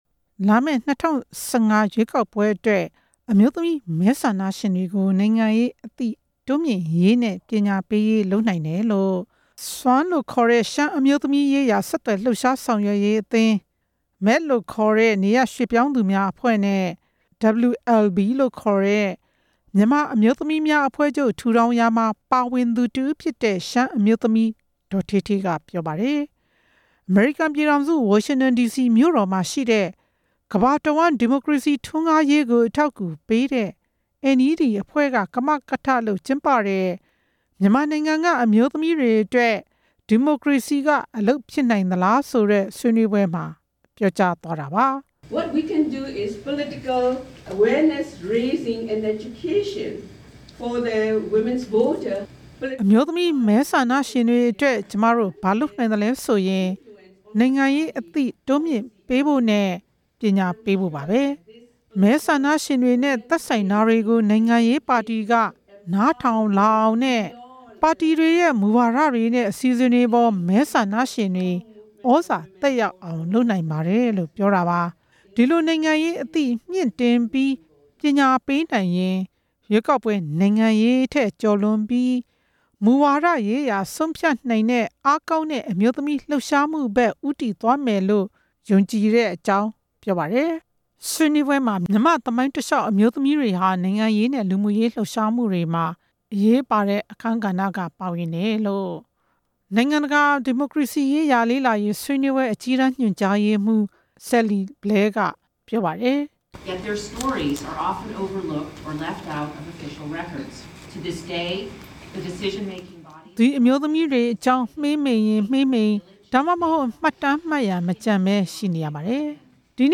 မြန်မာ အမျိုး သမီးရေးရာ ဆွေးနွေးပွဲအကြောင်း တင်ပြချက်